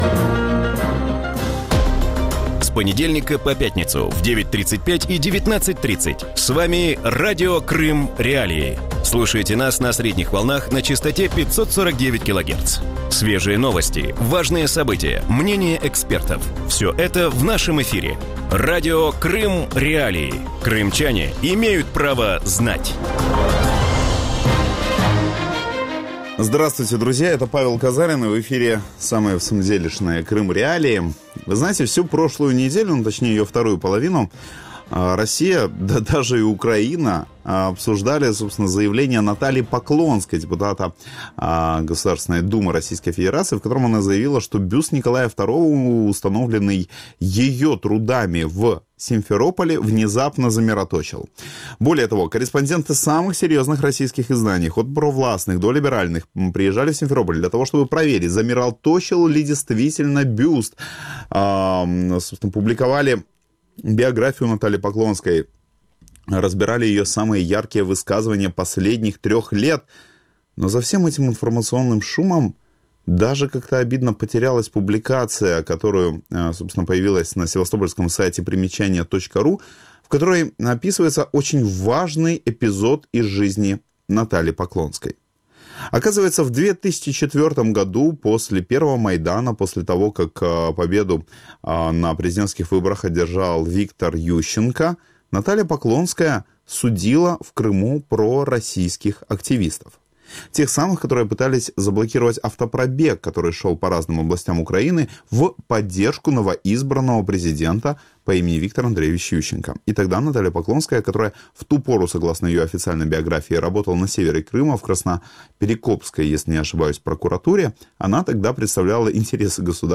В вечернем эфире Радио Крым.Реалии говорят о феномене Натальи Поклонской в российской политической жизни. Как Наталья Поклонская стала медийным персонажем?